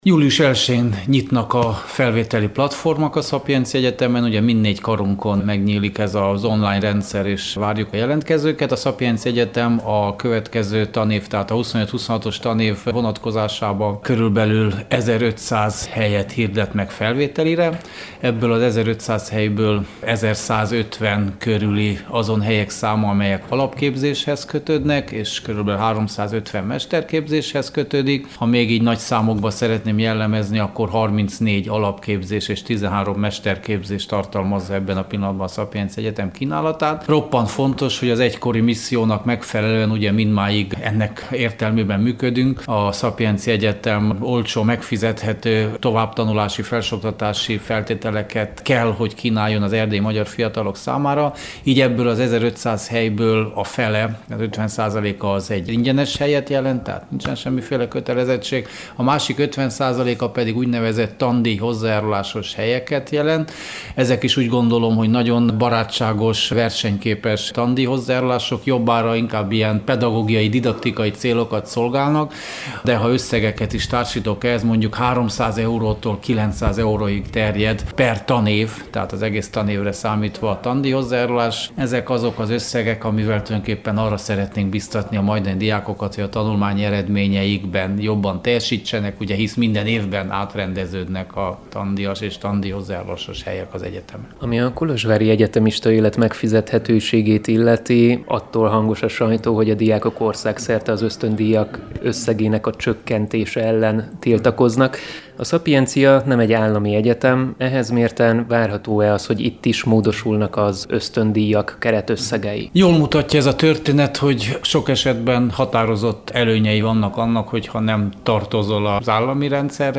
Riporter